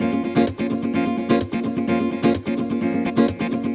VEH3 Nylon Guitar Kit 1 - 4 A# min.wav